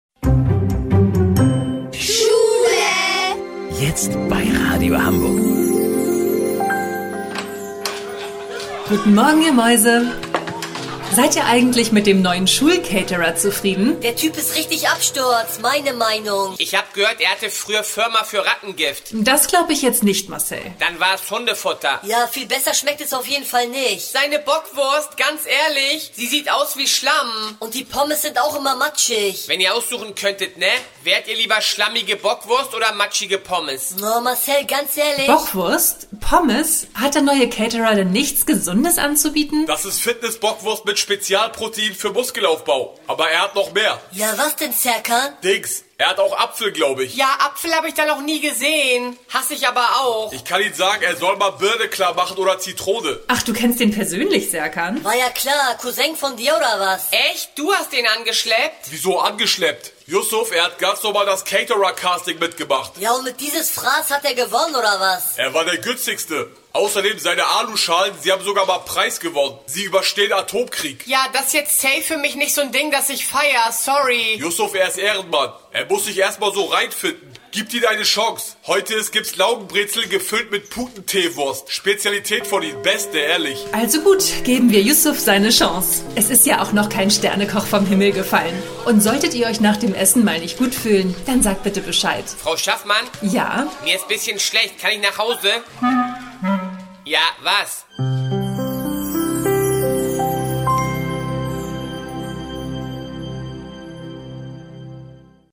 Comedy
Die Antworten klingen nicht so begeistert.